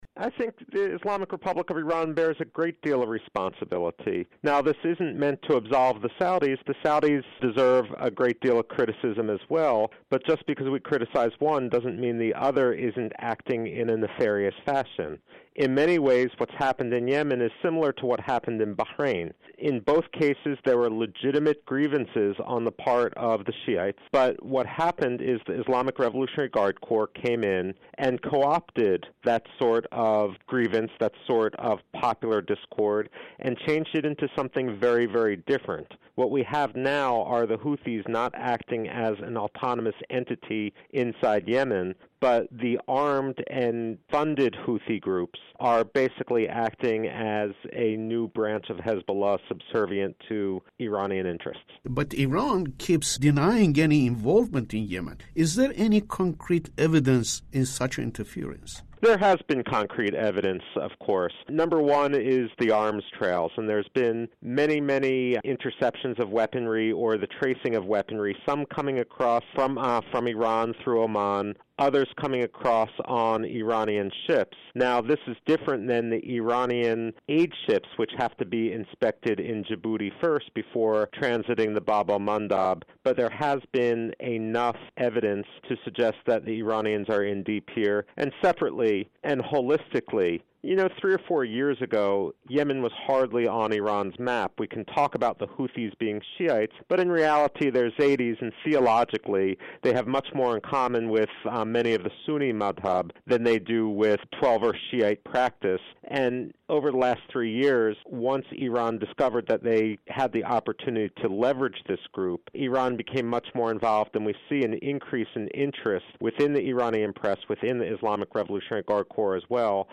Interview With Michael Rubin On Yemen Conflict